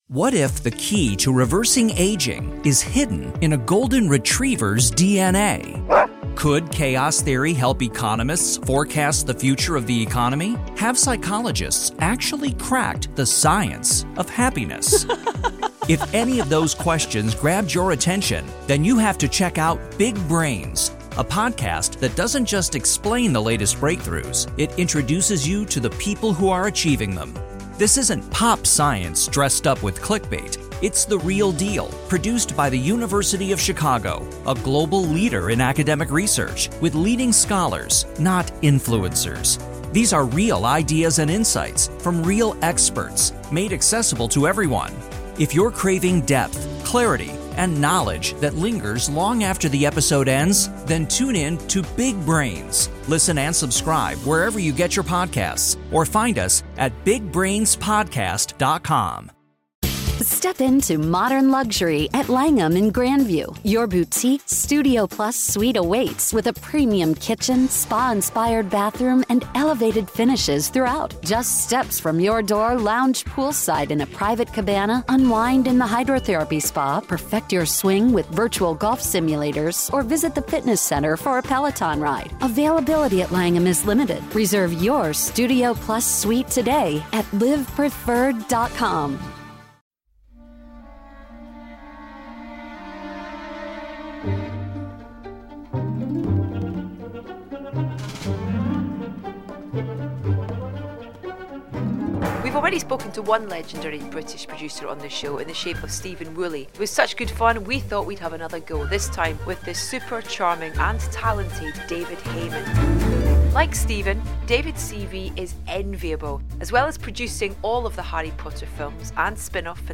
And given that we kick off by playing tracks and a clip from the film, we should warn you that there's inevitably a fair bit of choice language in this episode ...